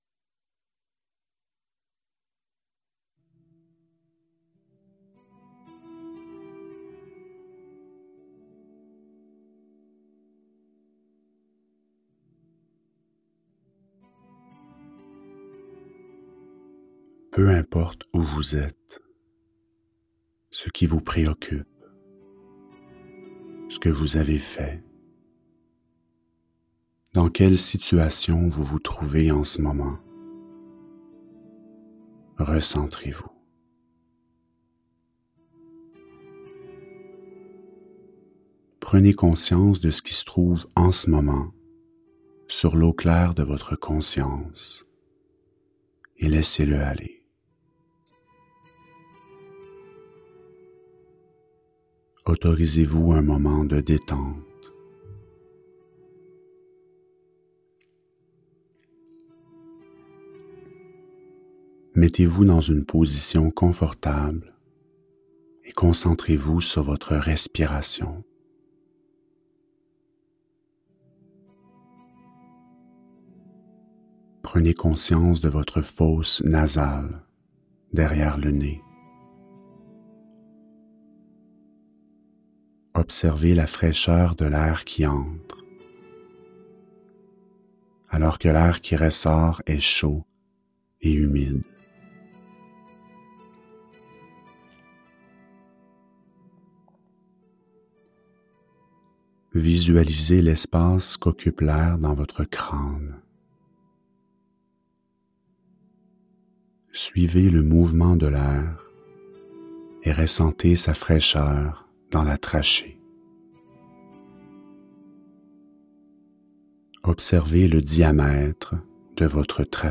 Méditations guidées - Vol. 2